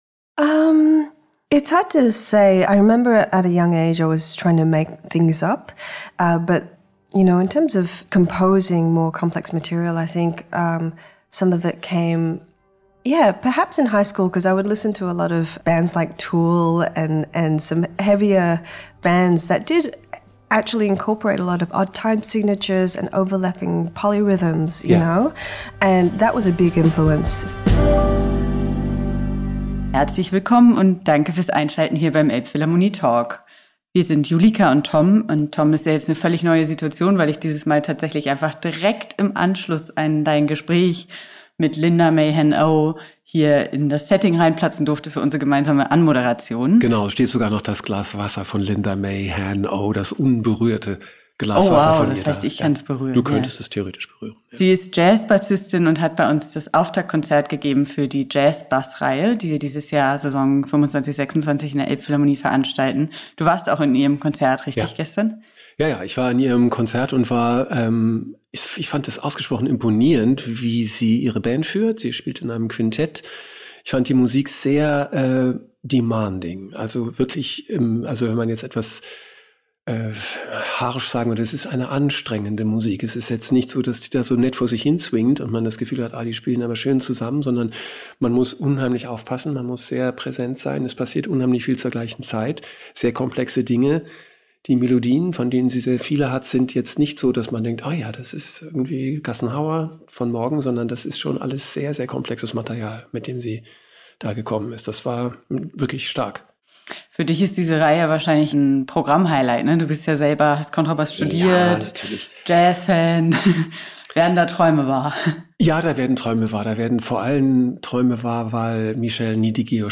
Mal Front-Frau, mal Sidewoman: Elbphilharmonie Talk mit der erfolgreichen Jazz-Bassistin Linda May Han Oh ~ Elbphilharmonie Talk Podcast